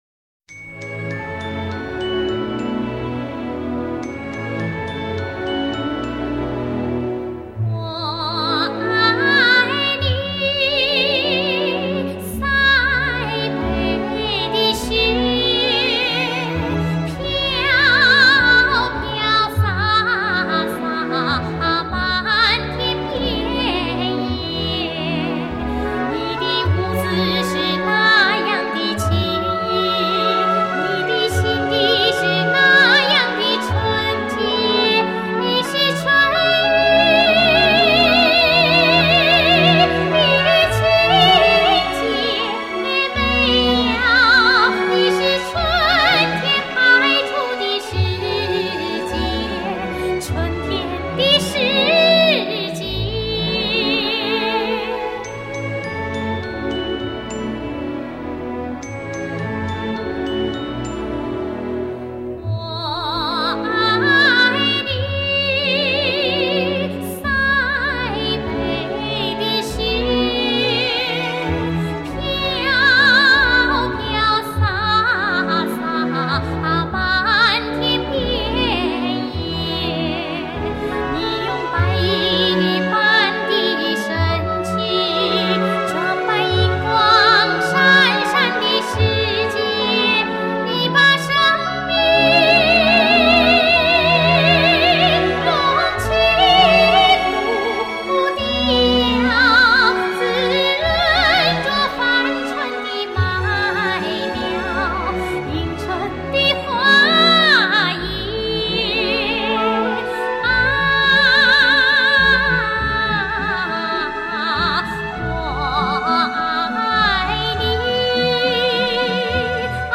给人以纯美欢愉、青春靓丽之感。